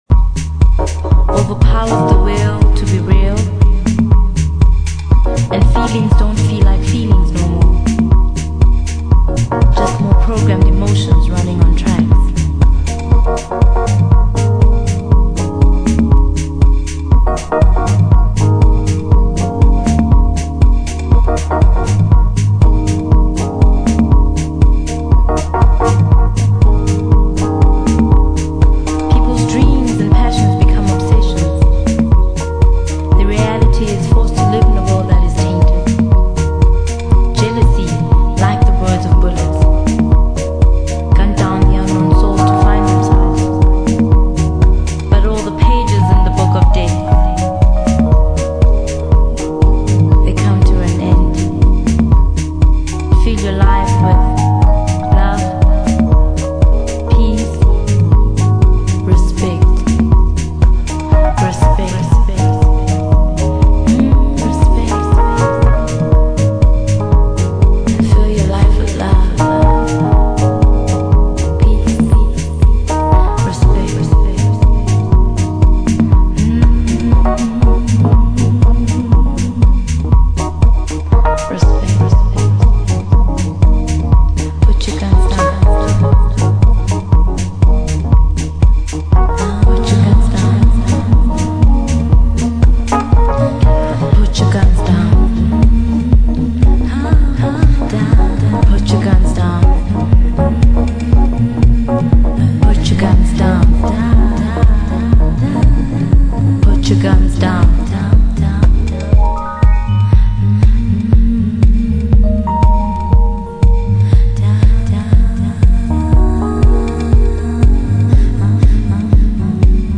南ア産ディープ・ハウス！！